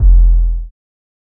Murda 808.wav